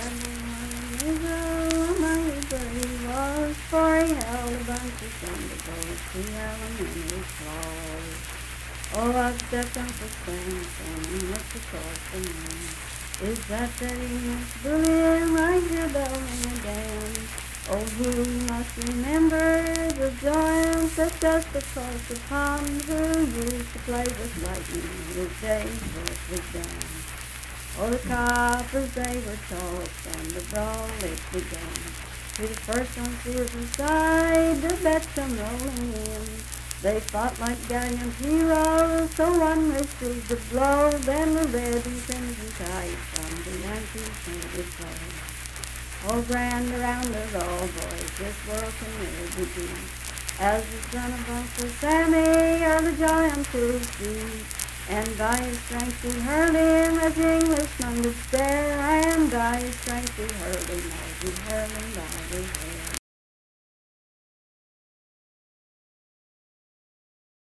Unaccompanied vocal music
Political, National, and Historical Songs
Voice (sung)
Braxton County (W. Va.), Sutton (W. Va.)